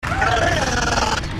Audiodatei der Sprache der Nakai
Alien_(SGU_1x11)_Sprache.mp3